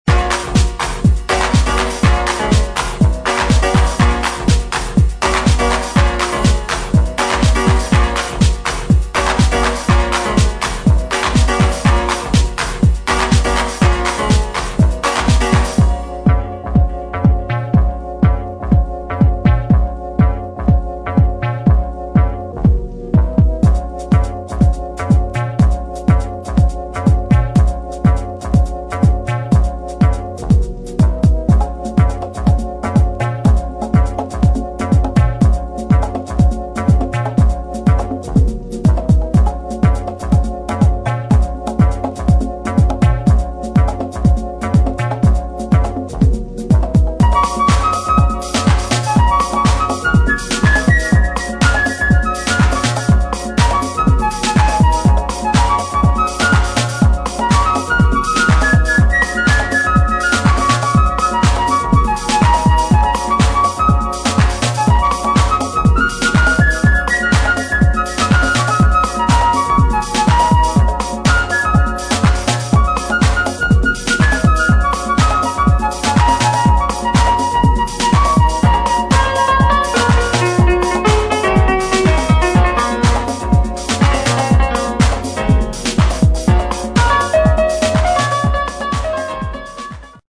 [ HOUSE / DEEP HOUSE ]